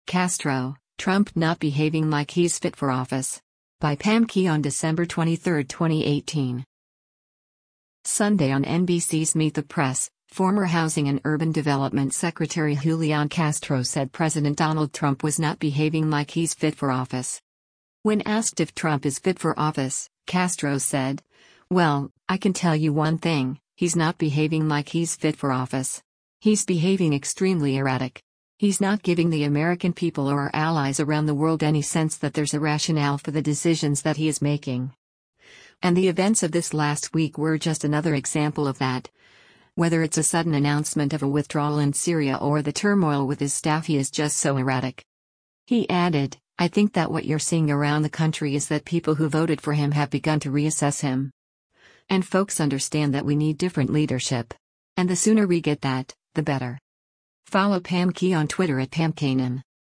Sunday on NBC’s “Meet The Press,” former Housing and Urban Development Secretary Julián Castro said President Donald Trump was “not behaving like he’s fit for office.”